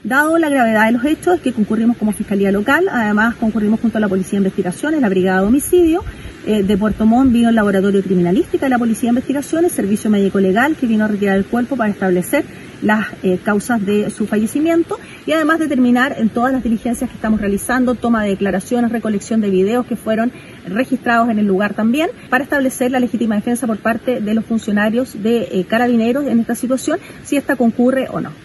fiscal-de-miguel-muerto-2.mp3